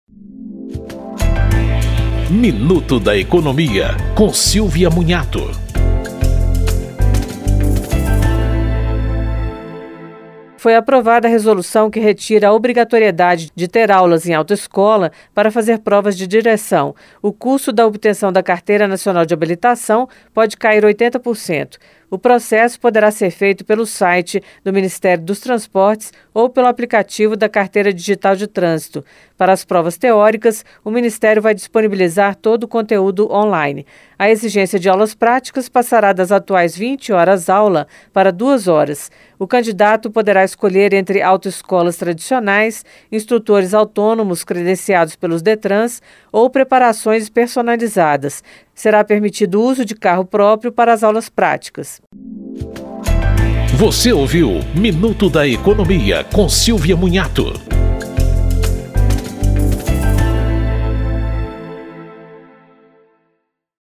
Em um minuto, dicas sobre direitos do consumidor, pagamento de impostos e investimentos.